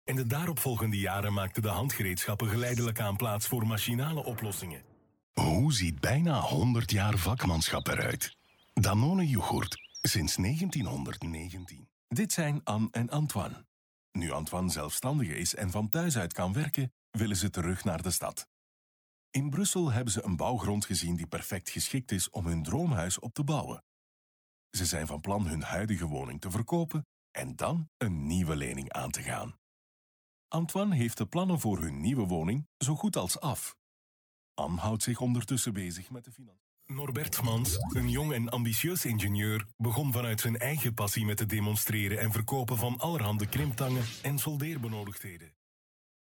Professionelle Sprecher und Sprecherinnen
Belgisch
Männlich